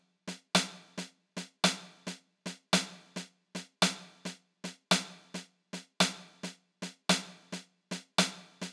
13 Snare.wav